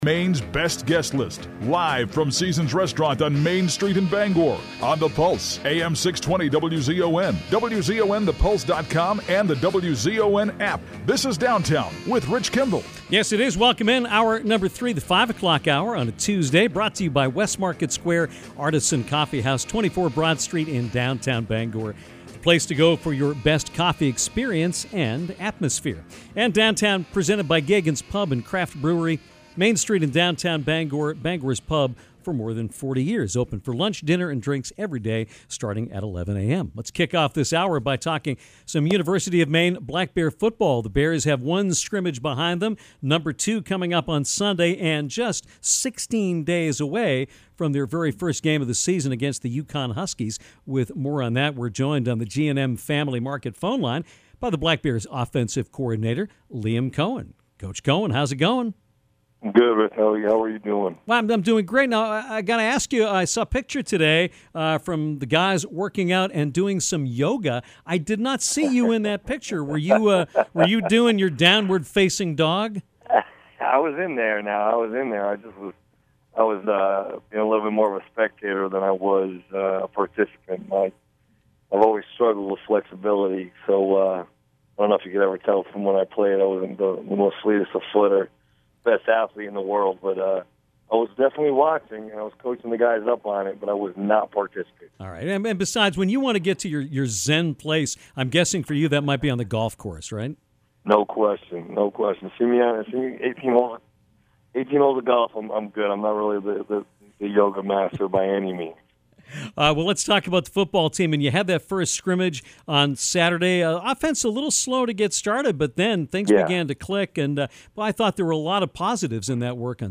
University of Maine OC Liam Coen visited the show on Tuesday afternoon and talked all things Black Bear football, giving us some insight into how camp is currently going. We talk about the first scrimmage, the quarterback battle, and the depth among the running back group.